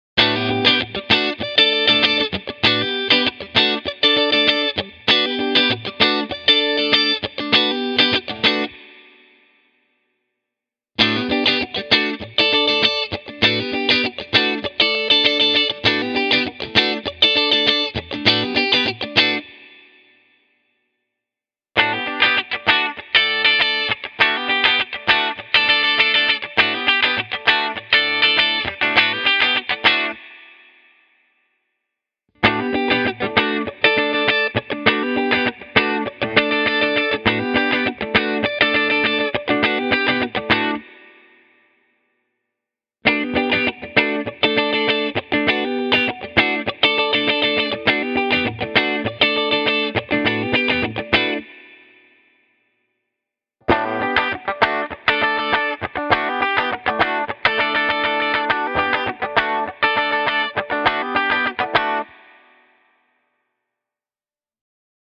The jump in output going from the neck to the bridge pickup sounds very organic.
In these soundbites you get the split humbucker selections first (neck, both, bridge) followed by the full humbuckers:
Schecter Solo-6 Custom – clean